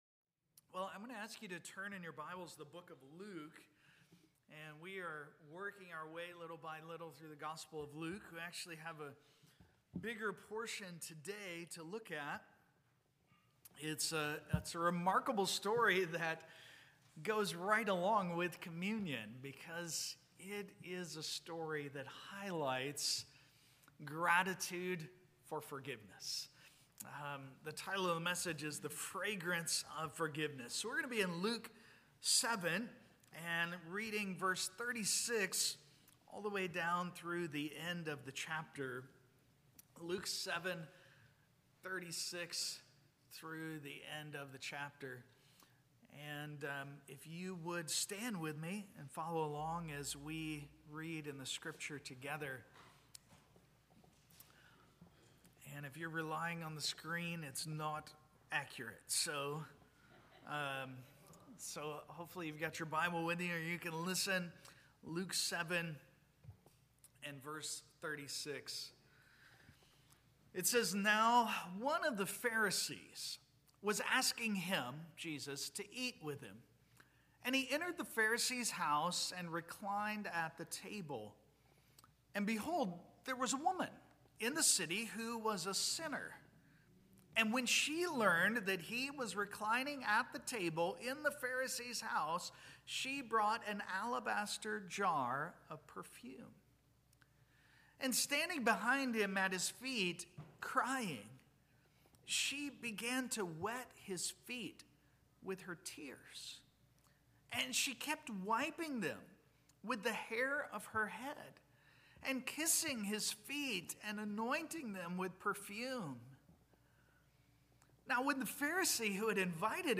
Luke Passage: Luke 7:36-50 Service Type: Sunday Morning « Exposing Unbelief How Do You React To Jesus?